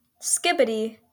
"Skibidi" (/ˈskɪbɪdi/